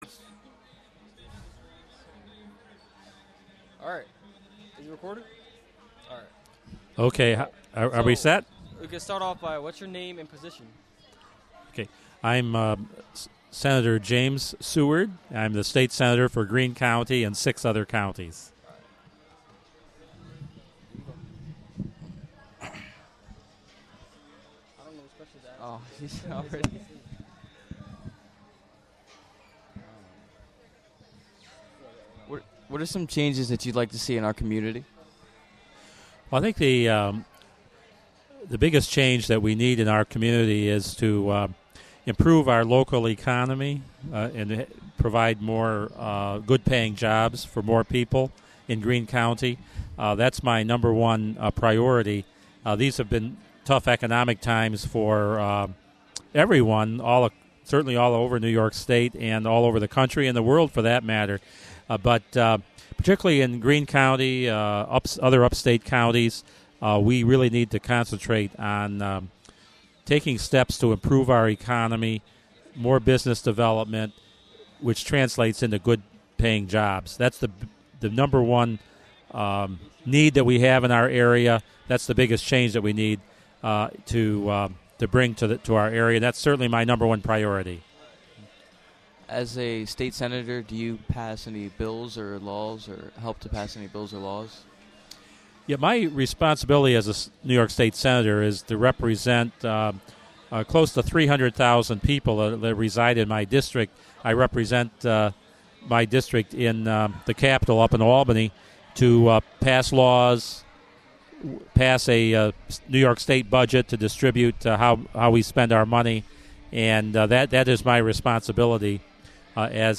WGXC at the Greene County Youth Fair: Jul 23, 2009 - Jul 26, 2009